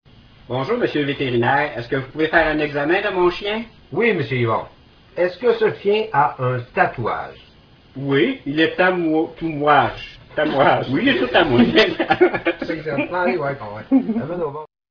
Je vous propose une version ratée (un blooper) de cette blague sur la boîte de droite...